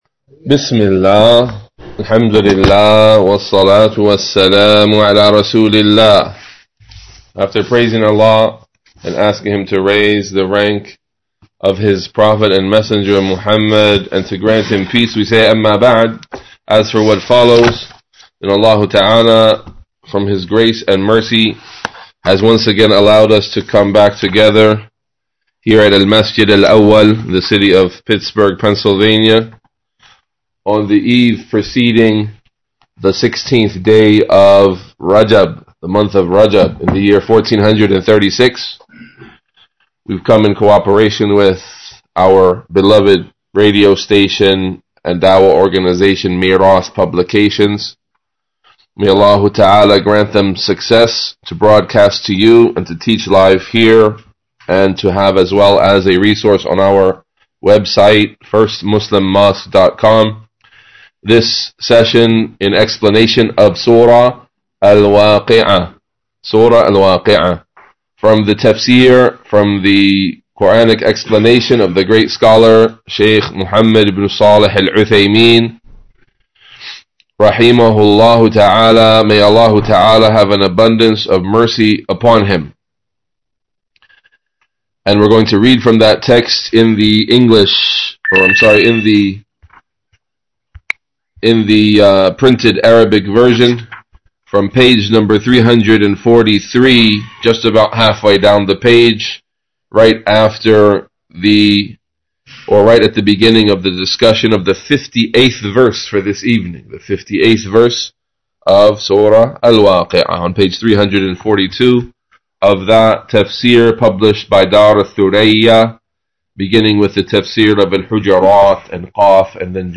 Category: TAFSIR
5.-Tafseer-Juz’-Thariyaat–Surah-56-Al-Waaqiah–Lesson5.mp3